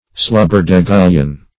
Search Result for " slubberdegullion" : The Collaborative International Dictionary of English v.0.48: Slubberdegullion \Slub"ber*de*gul`lion\, n. [Slubber + Prov.